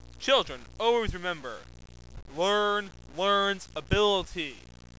In "real" life -- if you choose to call it real -- my voice is a sheep's bleat.
If you are a masochist, feel free to download these samples of my voice, simulating stupid stuff.